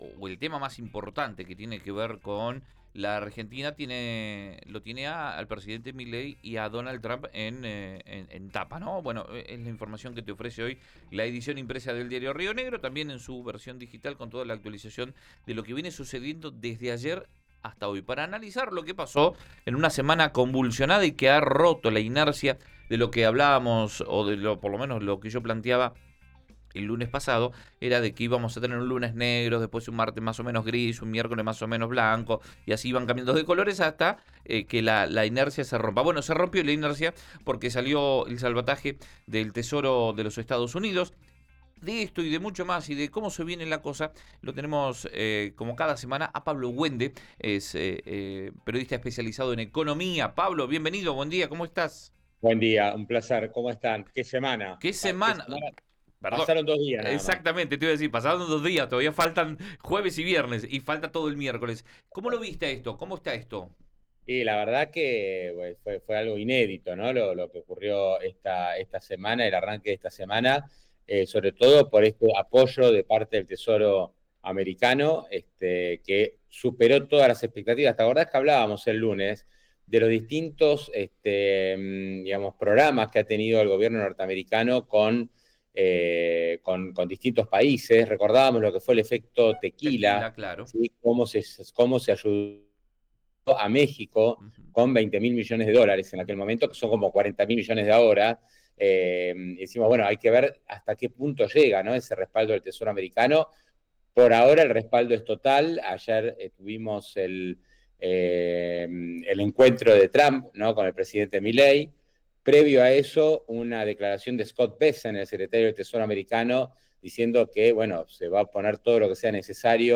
El especialista hizo un repaso de lo más importante que trae la semana en materia económica. En Río Negro Radio habló de la reducción de retenciones, dólar, riesgo país y la reunión con Donald Trump.